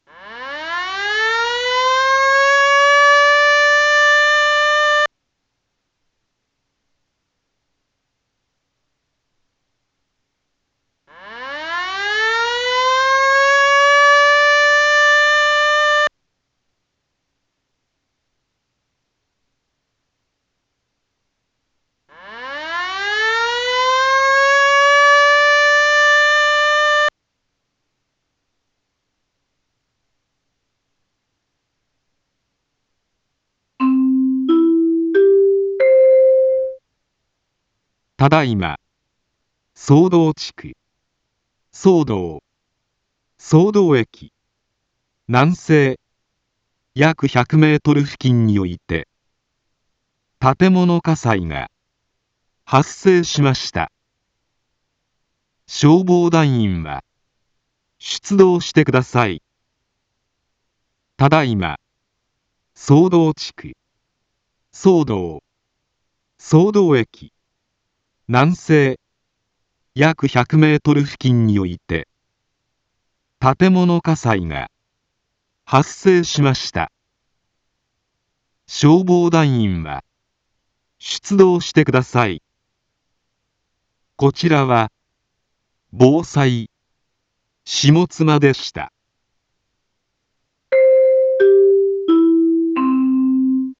一般放送情報
Back Home 一般放送情報 音声放送 再生 一般放送情報 登録日時：2026-01-02 19:21:55 タイトル：火災報 インフォメーション：ただいま、宗道地区、宗道、宗道駅南西約100メートル付近において、 建物火災が、発生しました。